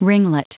Prononciation du mot : ringlet